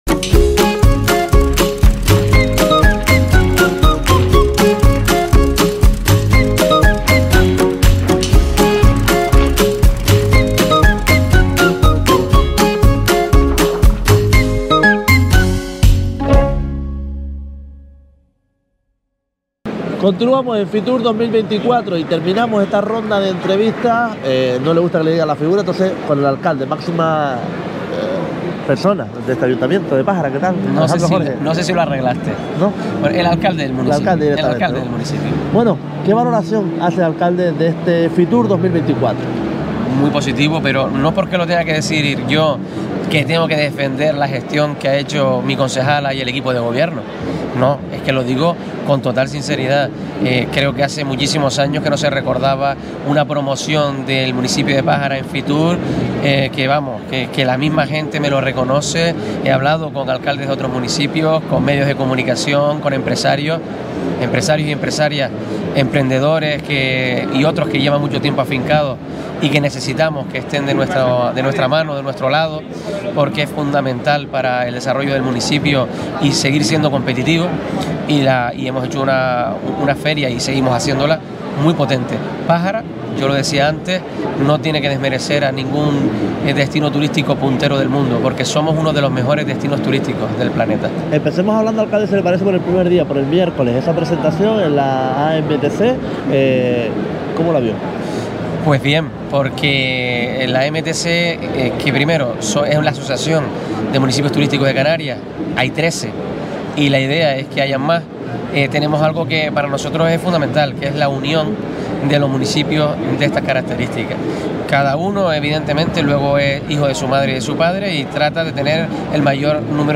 Fitur 2024: Entrevista a Alejandro Jorge
Terminamos con las entrevistas a los políticos de Fuerteventura con el Alcalde de Pájara, Alejandro Jorge Ya puedes escuchar el audio o descargarlo.
fitur-2024-entrevista-a-alejandro-jorge.mp3